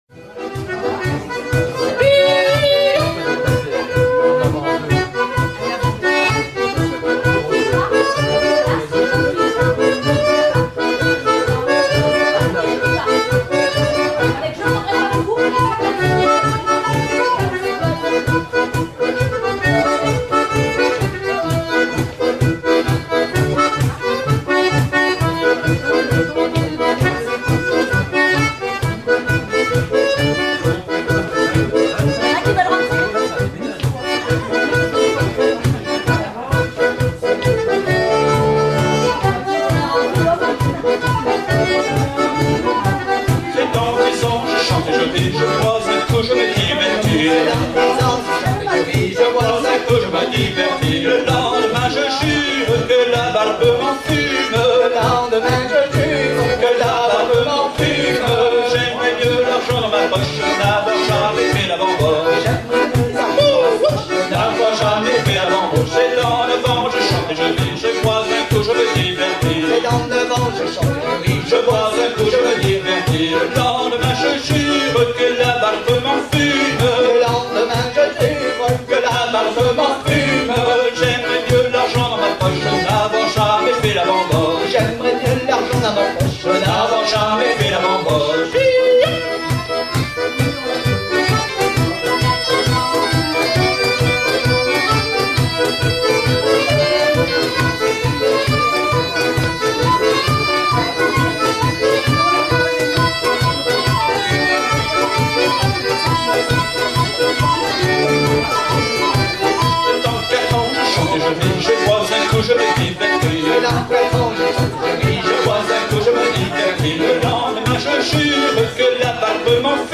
Le pas est basé sur une formule de quatre temps.